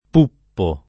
vai all'elenco alfabetico delle voci ingrandisci il carattere 100% rimpicciolisci il carattere stampa invia tramite posta elettronica codividi su Facebook poppare v.; poppo [ p 1 ppo ] — pop. tosc. puppare : puppo [ p 2 ppo ]